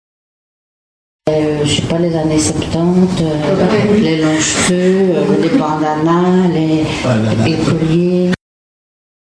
uitspraak Hippie.